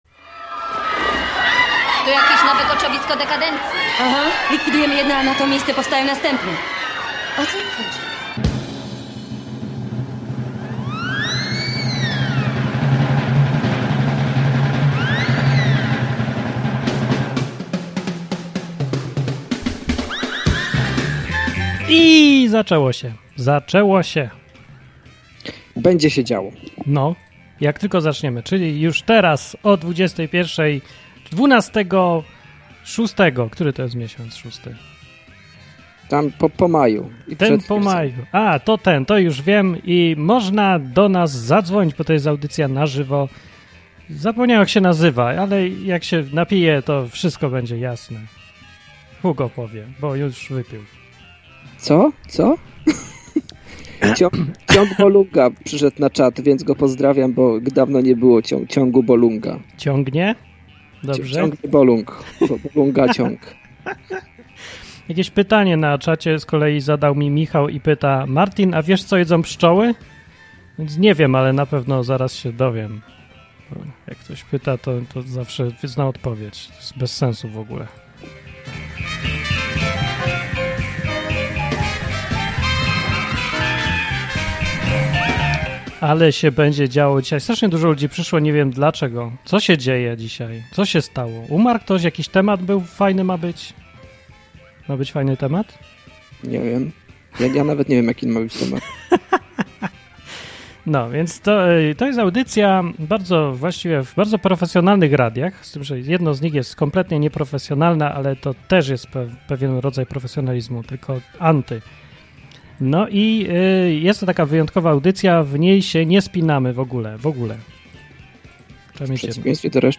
Na dowód tego w audycji gościmy reprezentantów 95% społeczeństwa.
Koczowisko Dekandencji to dwu-radiowa audycja, w której od luzu, sarkazmu i ironii wióry lecą.